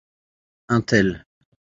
Pronúnciase como (IPA) /œ̃ tɛl/